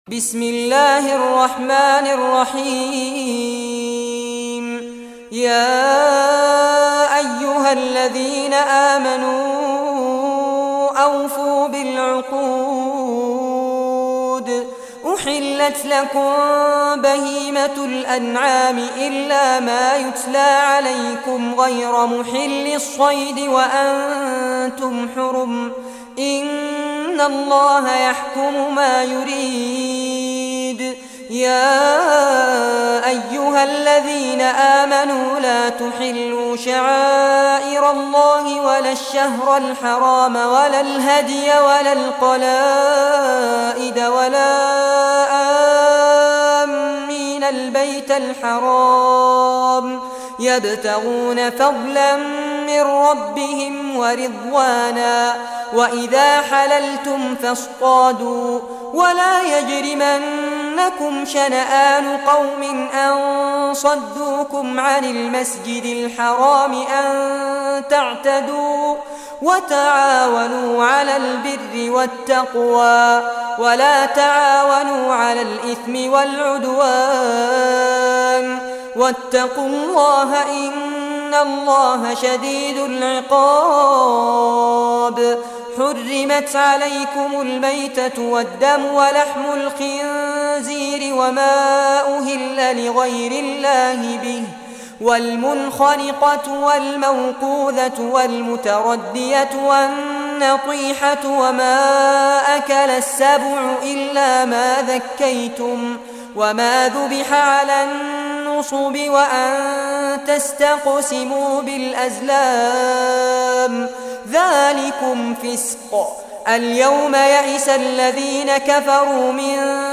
Surah Al-Maidah Recitation by Fares Abbad
Surah Al-Maidah, listen or play online mp3 tilawat / recitation in Arabic in the voice of Sheikh Fares Abbad.